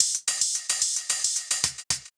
Index of /musicradar/ultimate-hihat-samples/110bpm
UHH_ElectroHatD_110-05.wav